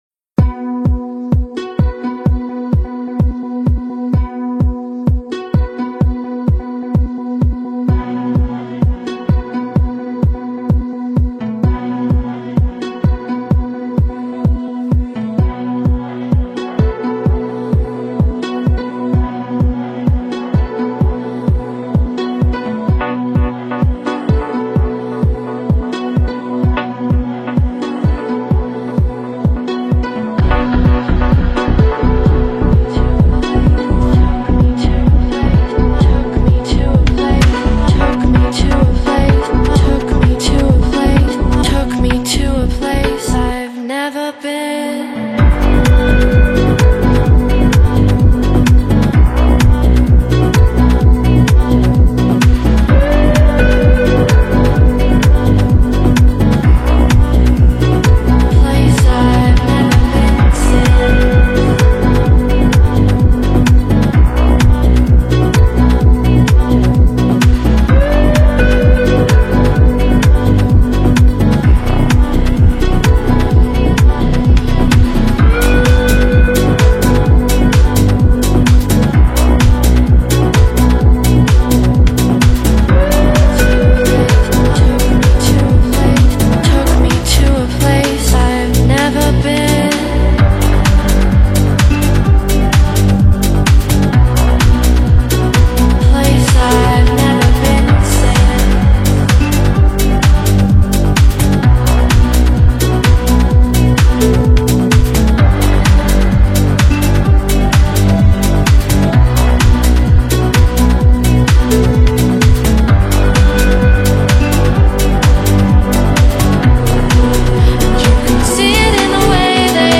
Psychedelic Rock